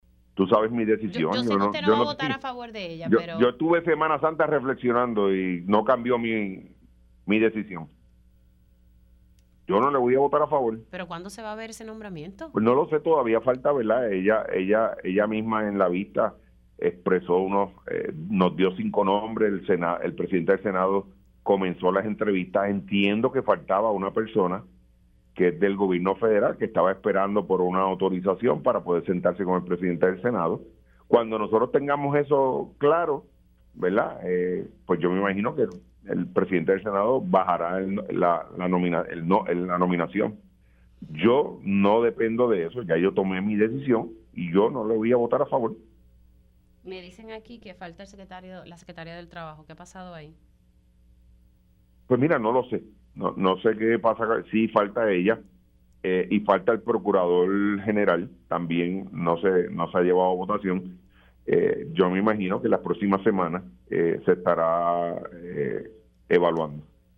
El portavoz alterno del Senado, Juan Oscar Morales indicó en Pega’os en la Mañana que se mantendrá firme en su oposición al nombramiento de la secretaria de Justicia, Janet Parra.
209-JUAN-OSCAR-MORALES-SENADOR-PNP-EN-LAS-PROXIMAS-SEMANAS-SE-EVALUA-NOMBRAMIENTO-DE-JANET-PARRA-LE-VOTARA-EN-CONTRA.mp3